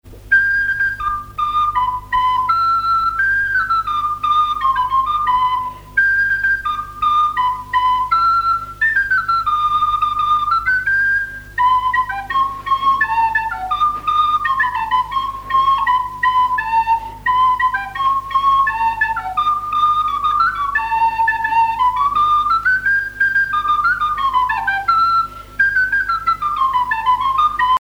Mémoires et Patrimoines vivants - RaddO est une base de données d'archives iconographiques et sonores.
danse : quadrille : pantalon
airs de danse à la flûte
Pièce musicale inédite